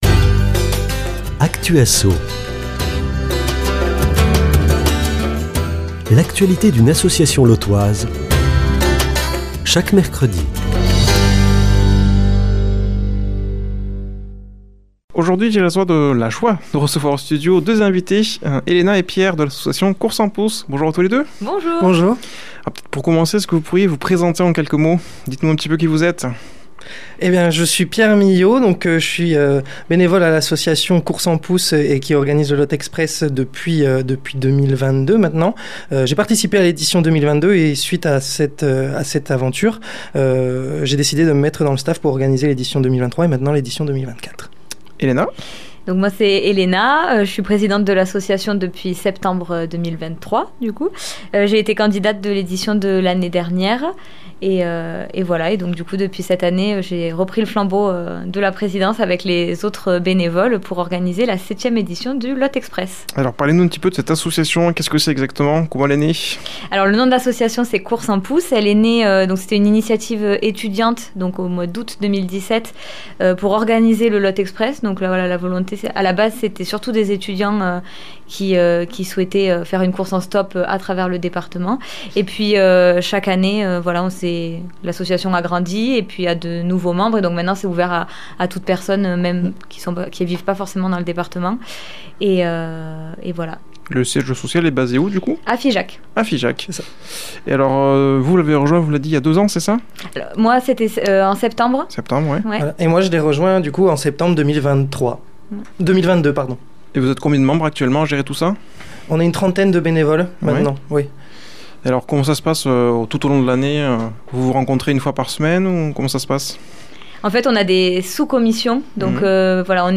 reçoit au studio